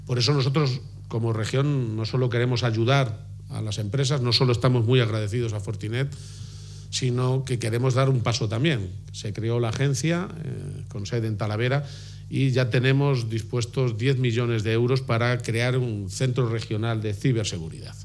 >> García-Page anuncia la medida durante la inauguración del centro de datos de Fortinet en Torija, Guadalajara